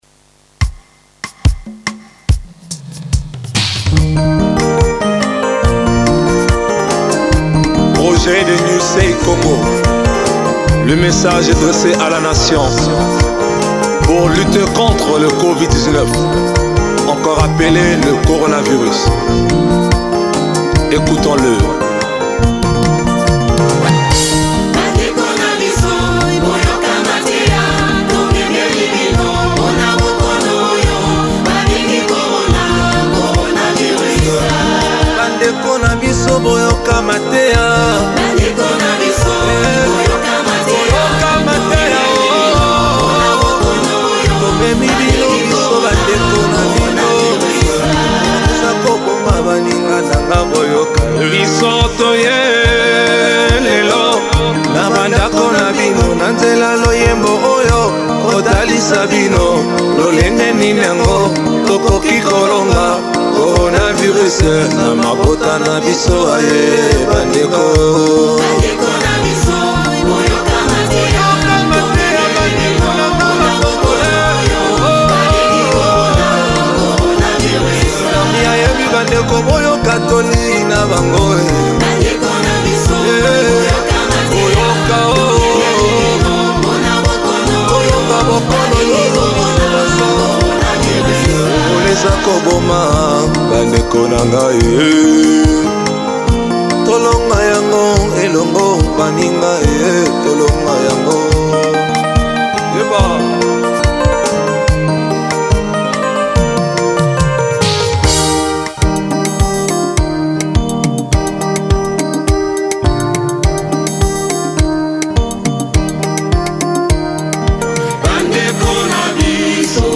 La version instrumentale en studio est là maintenant!
The studio version is here now!
The nursing students of New Sight Eye Care have written a song in Lingala (the local language) to help spread word about the preventive measures needed to combat Coronavirus. They are joined by the members of the local basketball team ‘The Black Panther Club”.